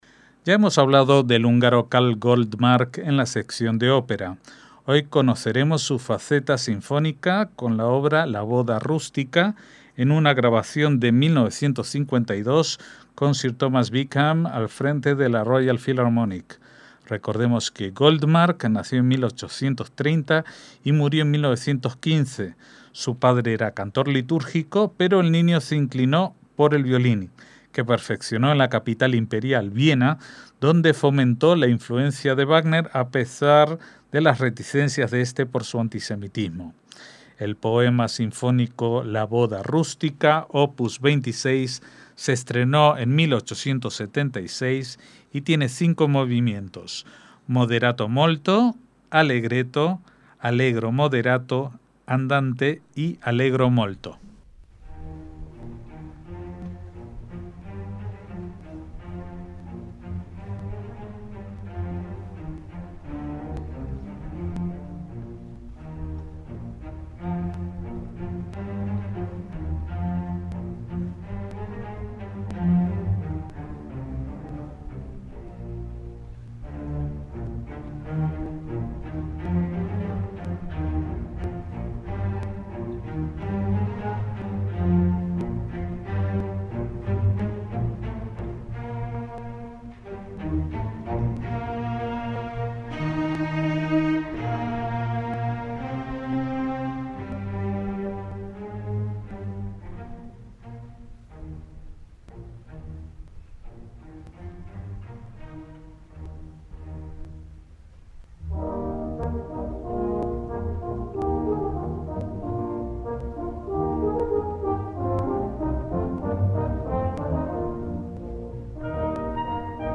Poema sinfónico "La boda rústica", de Karl Goldmark